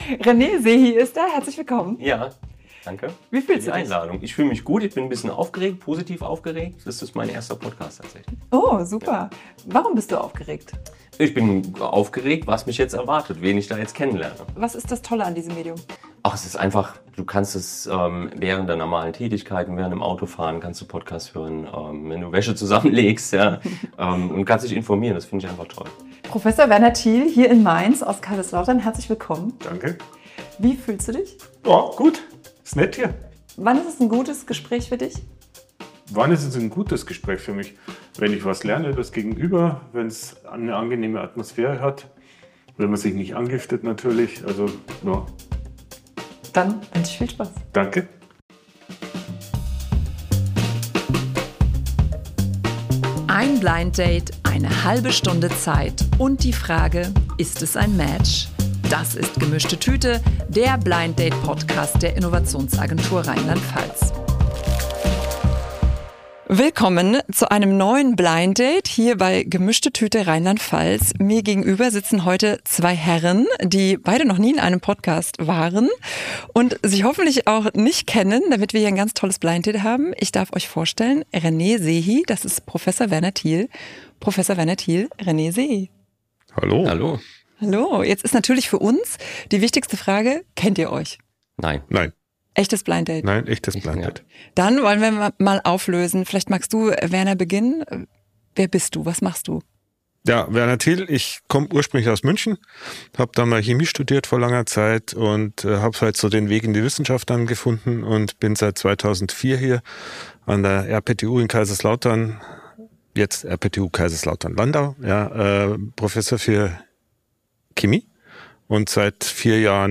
Im Podcast der Innovationsagentur RLP treffen sich zwei Persönlichkeiten, die sich bisher nicht kennen, und tauschen sich über ihre unterschiedlichen Perspektiven aus. Das Besondere: Sie bringen ein Geschenk mit, das etwas über sie erzählt.